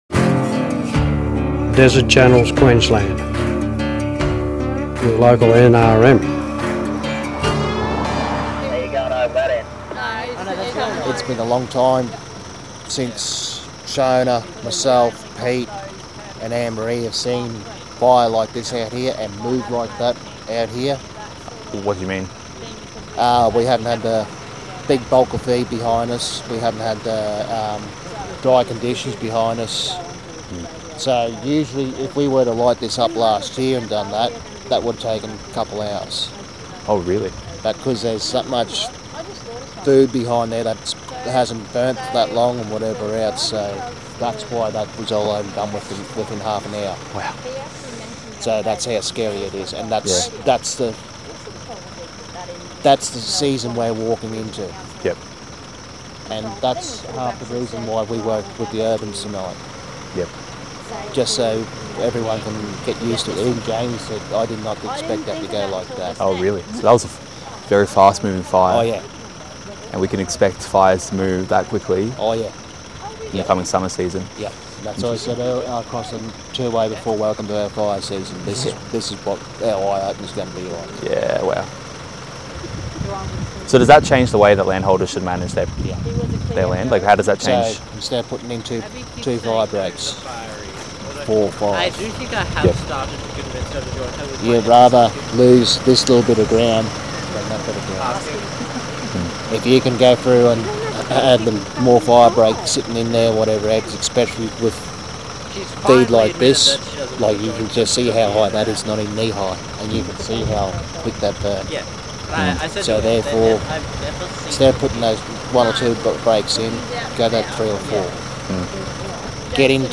The hazard reduction burn was conducted along stock route near the Landsborough Highway at dusk.